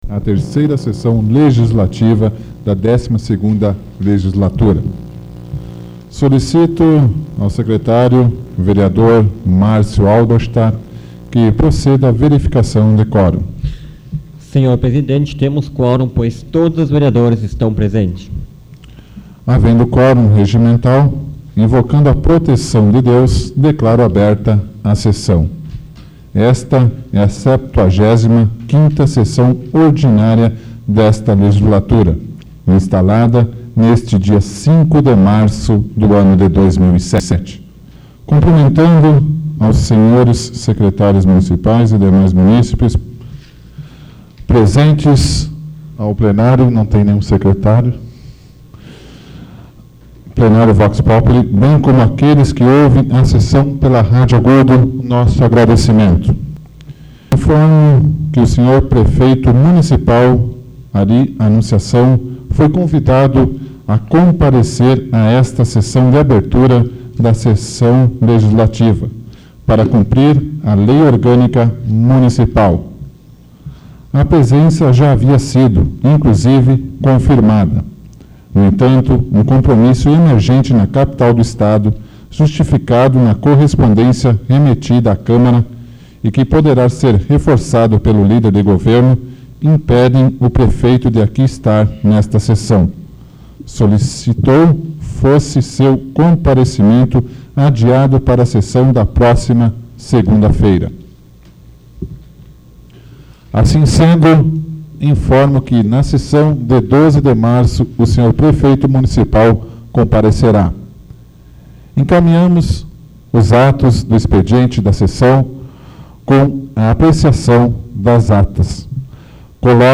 Áudio da 75ª Sessão Plenária Ordinária da 12ª Legislatura, de 05 de março de 2007